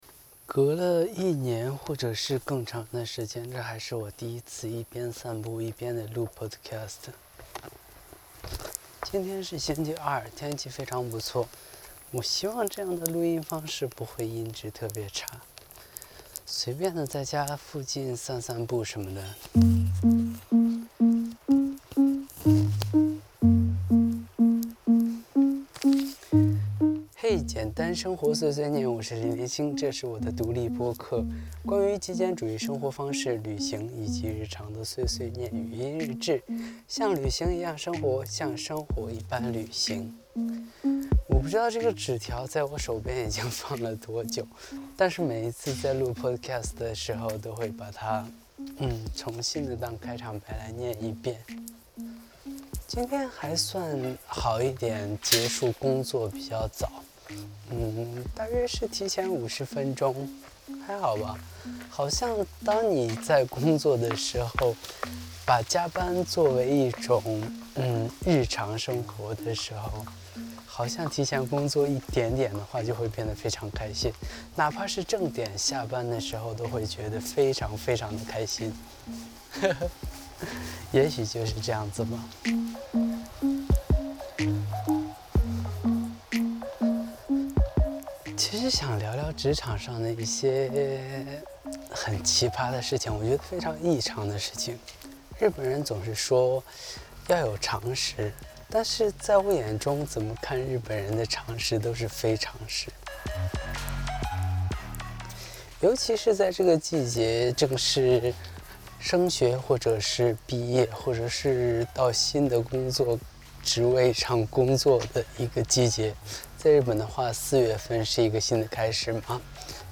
散步中的简单碎碎念， 念念日本异常的事情。